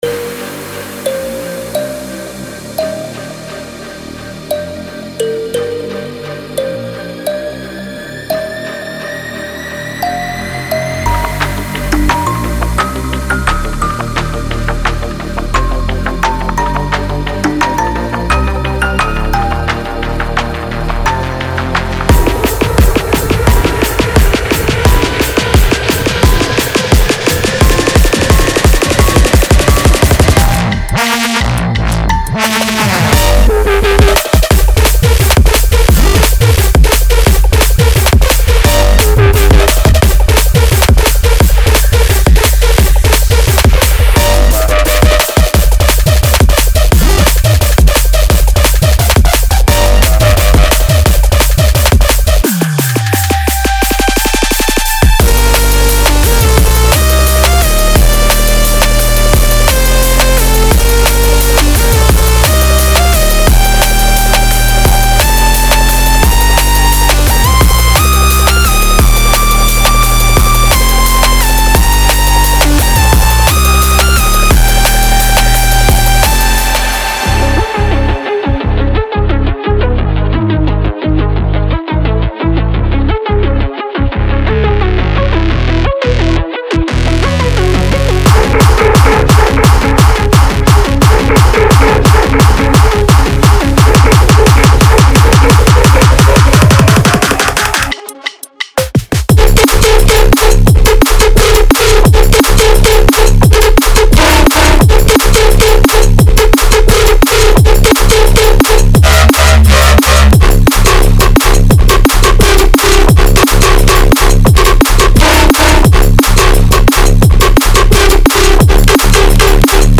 Genre:Drum and Bass
ループのテンポは170〜174BPMの範囲で、あらゆるドラムンベース制作に最適であることを保証します。
デモサウンドはコチラ↓